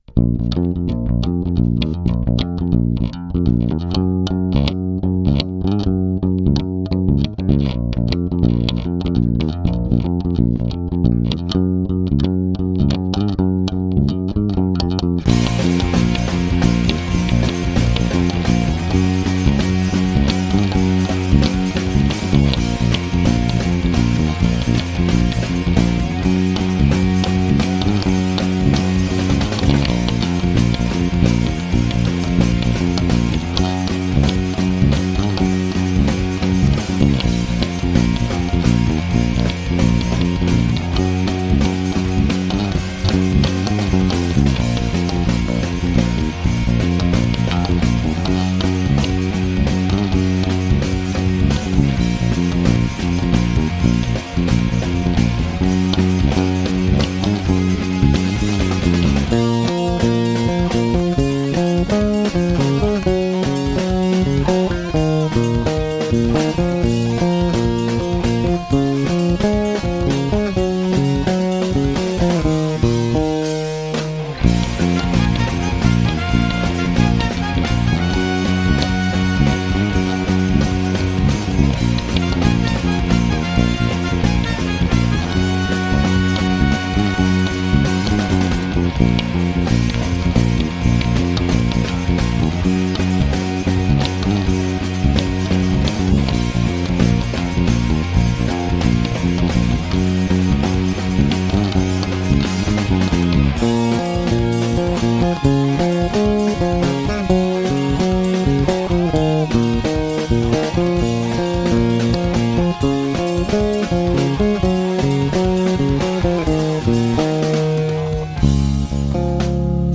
instrumental
Bass only